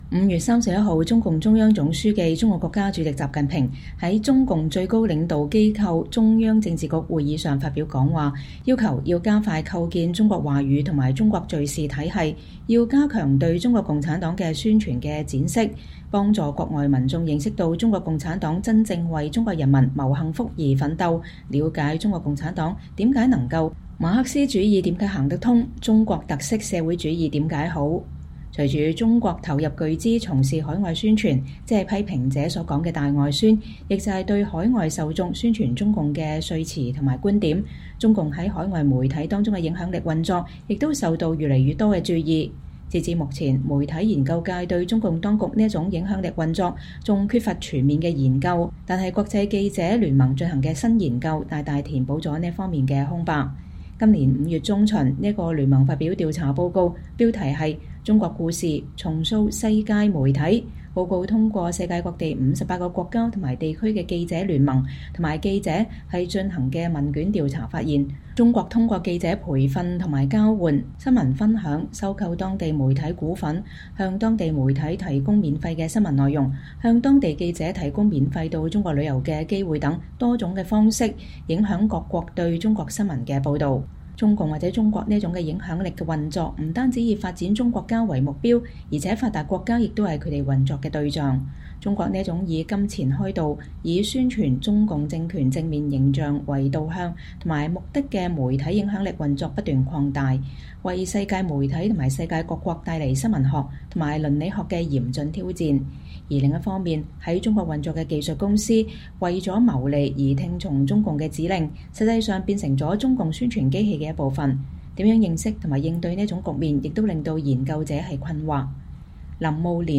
專訪媒體研究學者林慕蓮談中國重塑世界媒體